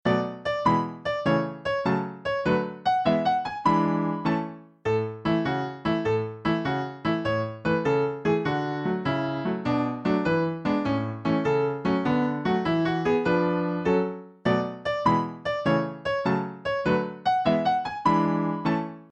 Sheet Music — Piano Solo Download
Piano Solo
Downloadable Instrumental Track